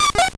Cri de Coxy dans Pokémon Or et Argent.